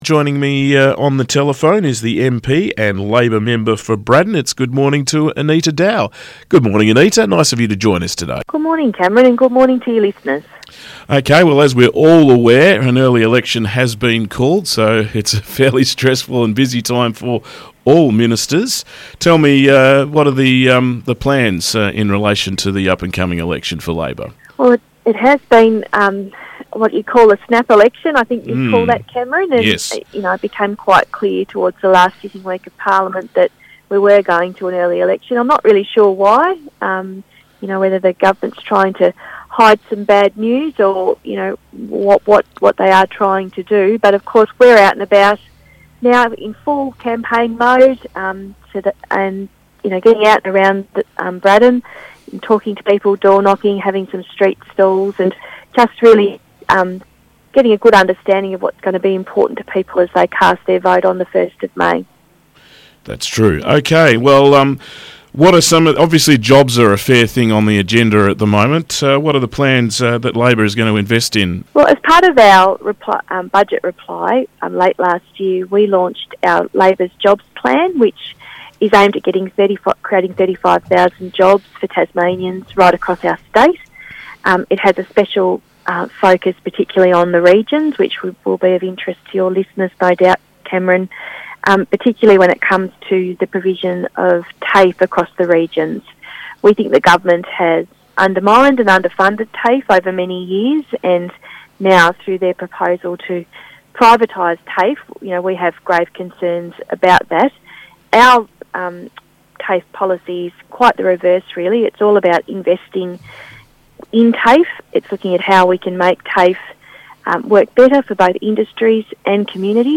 had a chat with MP, Labour member for Braddon, Anita Dow